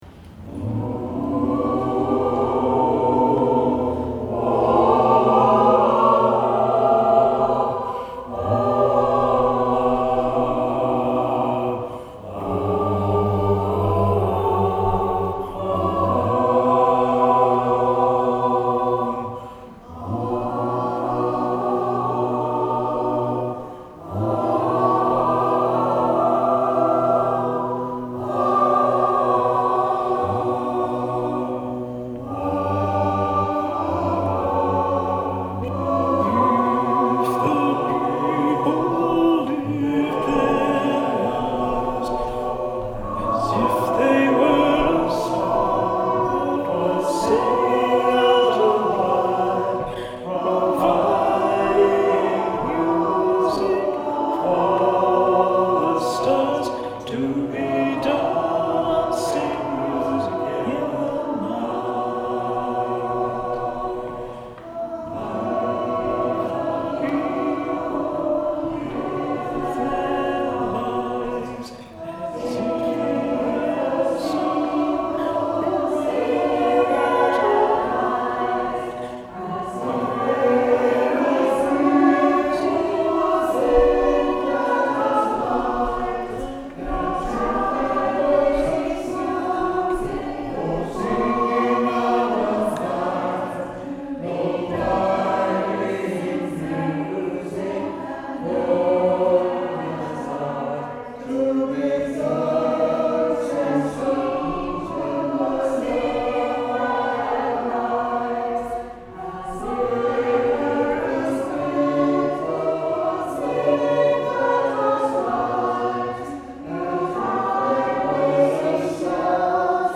The recordings are a very rough and I’d forgotten to turn off autogain (doing two things at once: very silly) while recording, so I’ll hope you forgive the brassy sound.
Hymn for the Russian Earth This is slower than it probably should be, but it’s early days for the choir